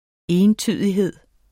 Udtale [ ˈeːnˌtyˀðiˌheðˀ ]